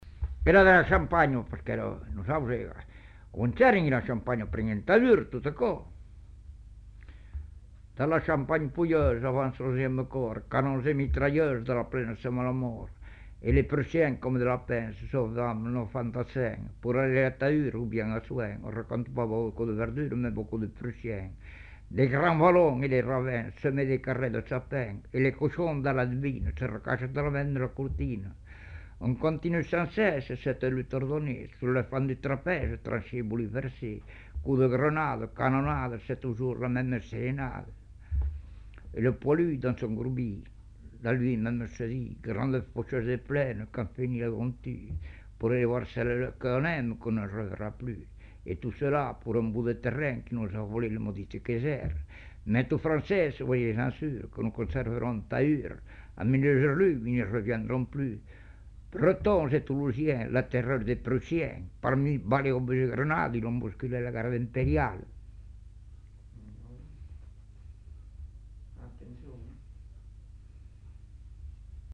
Aire culturelle : Savès
Département : Gers
Genre : chant
Effectif : 1
Type de voix : voix d'homme
Production du son : récité
Classification : chanson de la guerre 14-18